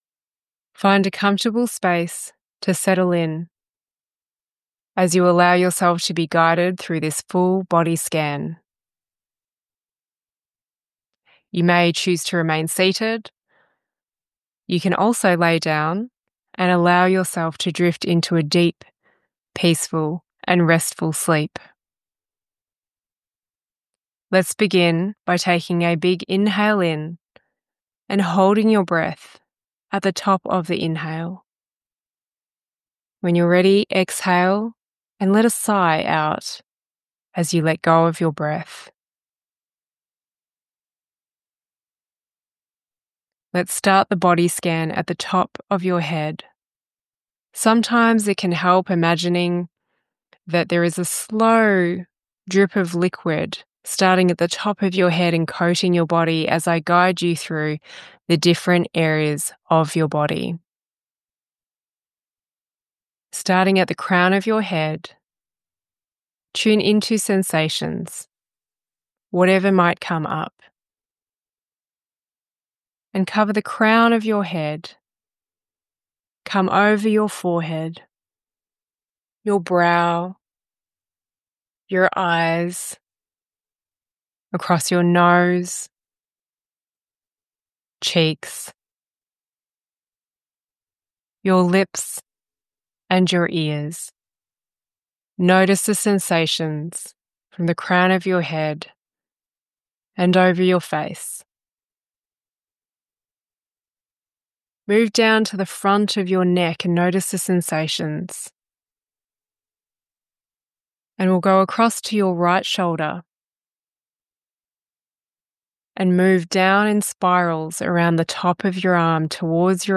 Body Scan audio final.mp3